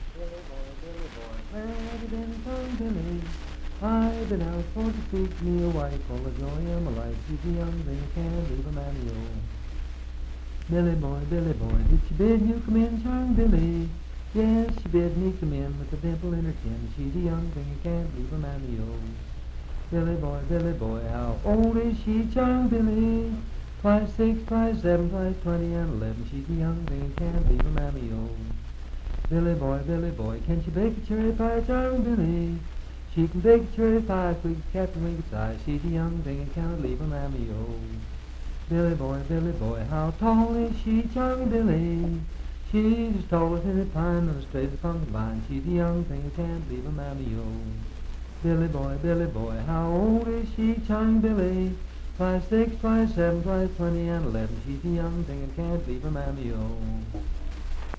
Unaccompanied vocal music
Children's Songs, Dance, Game, and Party Songs
Voice (sung)
Marlinton (W. Va.), Pocahontas County (W. Va.)